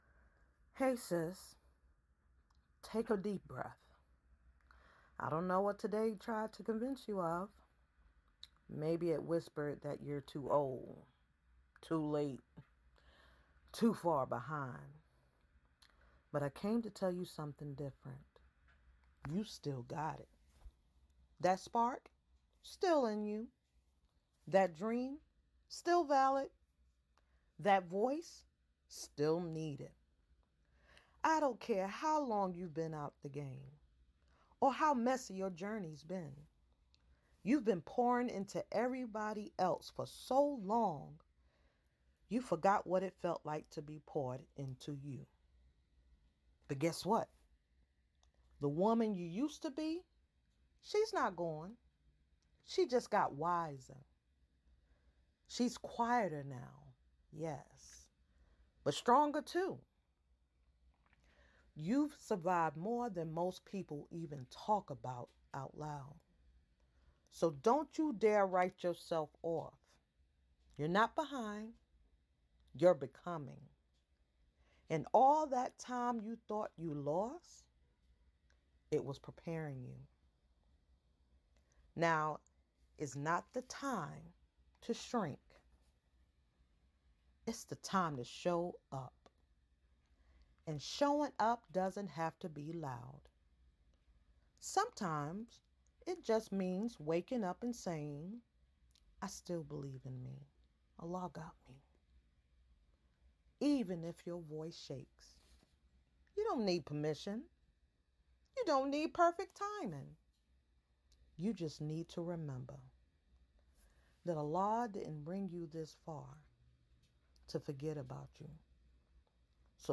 Voice Notes for Women 40+ who've been humbled by life